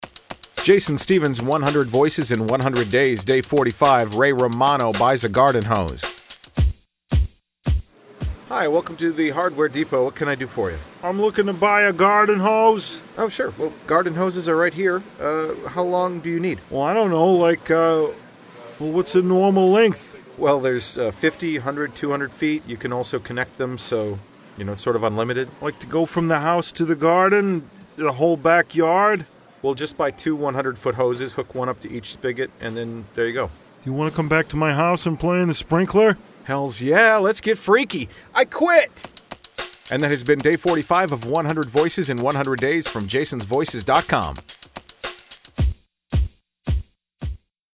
For today’s episode, my Ray Romano impression is on the table – facing a problem that most homeowners have dealt with (this was basically a verbatim reenactment of the first time I bought a garden hose).
Tags: celebrity sound alike, Ray Romano impression